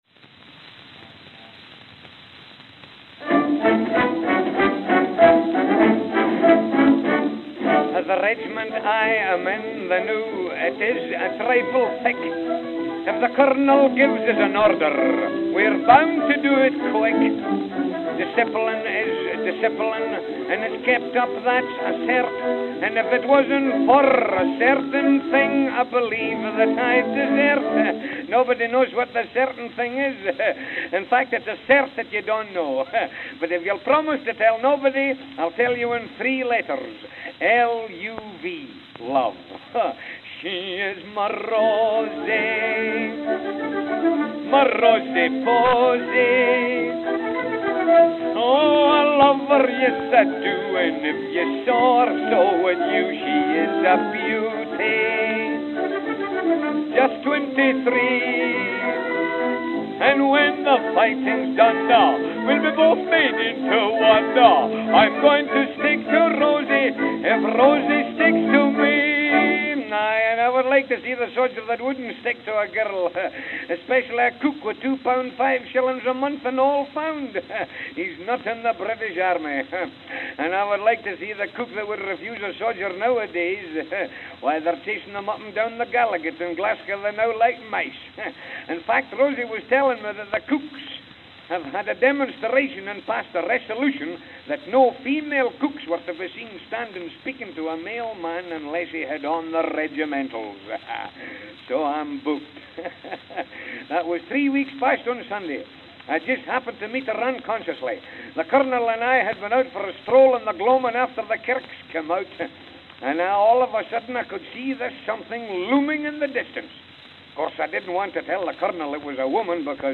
November 20, 1915 (New York, New York) (4/4)